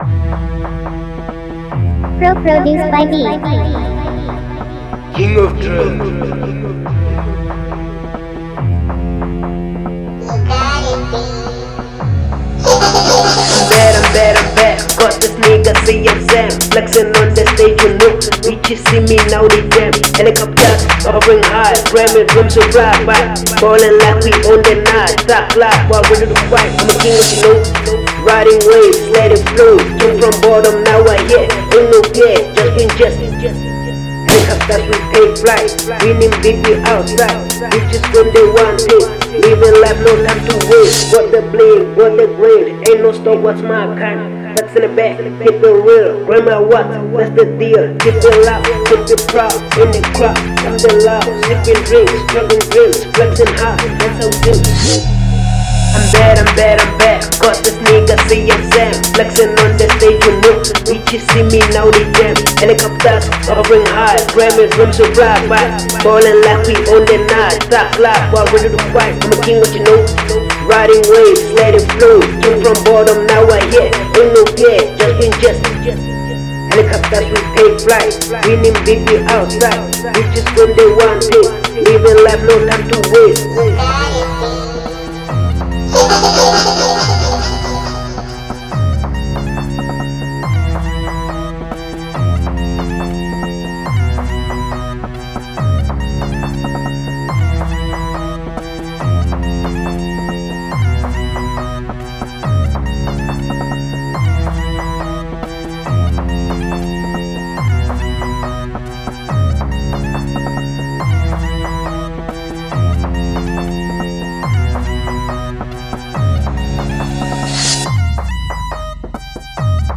hiphopdrill